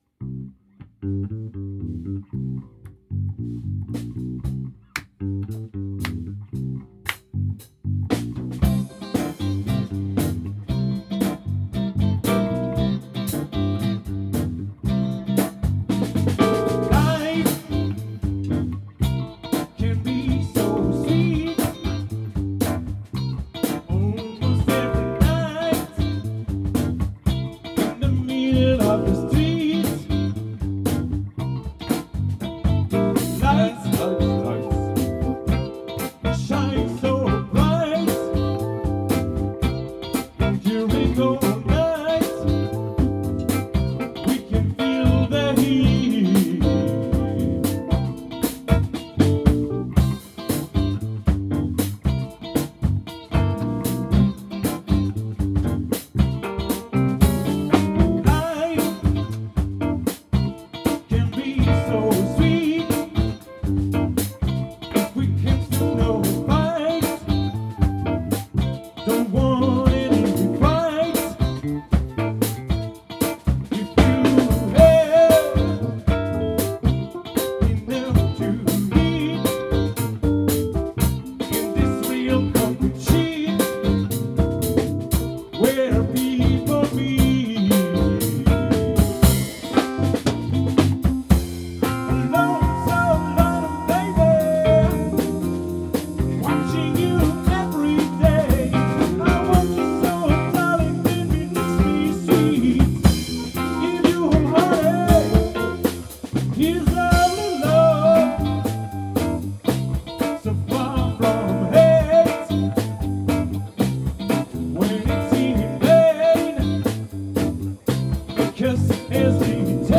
Couplet : C7, 16M
Refrain : F7, 8M
Pont : Bb7, 8M + G7, 2M